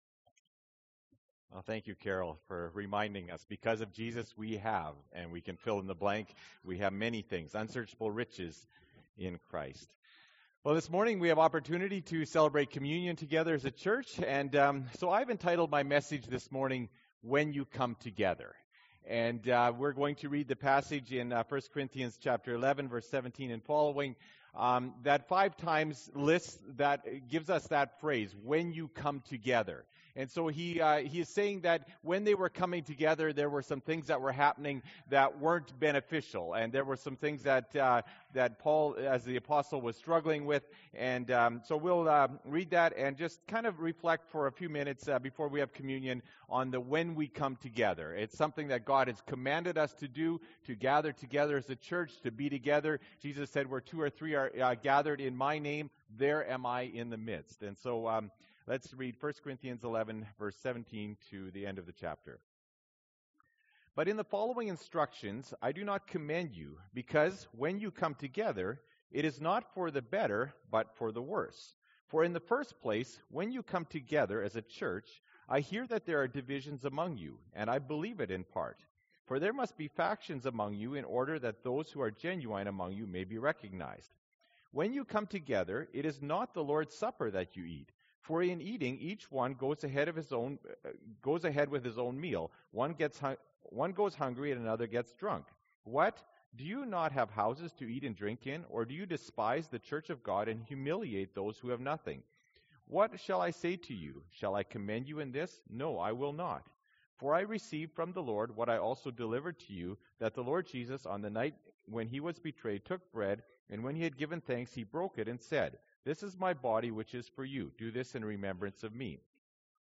Nov. 22, 2015 – Sermon